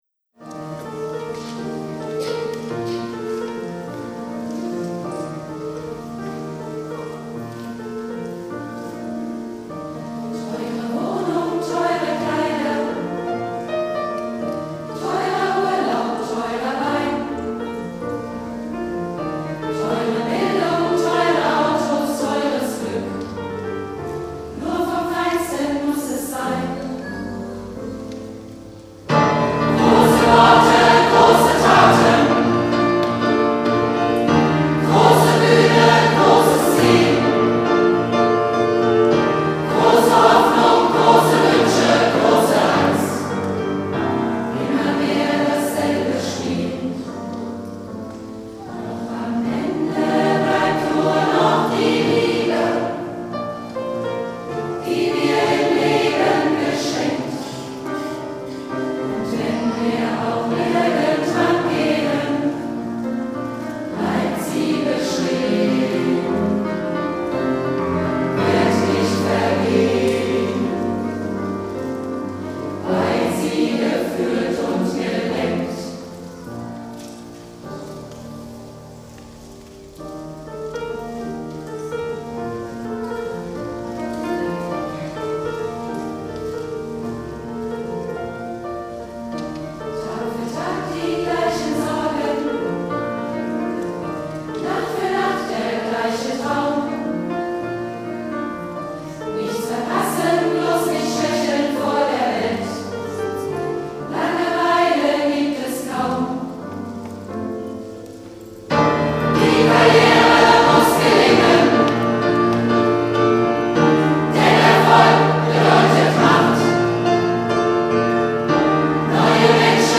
Am-Ende-bleibt-nur-noch-die-Liebe-LIVE.mp3